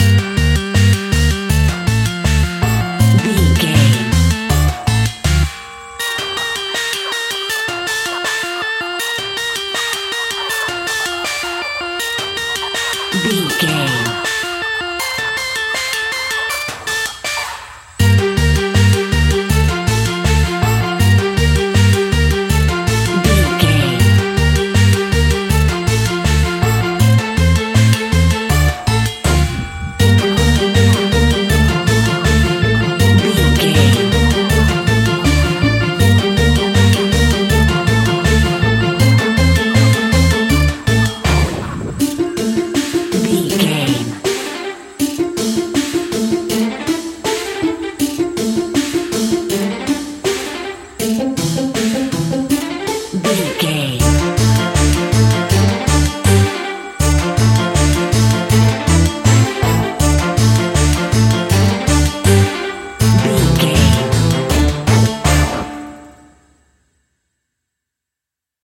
royalty free music
Aeolian/Minor
D
ominous
eerie
synthesiser
drum machine
spooky
horror music